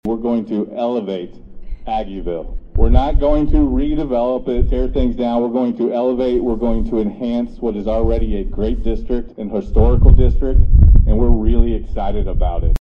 City officials, contractors, developers, employees, and more gathered Thursday in the parking lot behind Kite’s Bar and Grill for the official groundbreaking of the Midtown Development project.